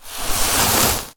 fireball_conjure_04.wav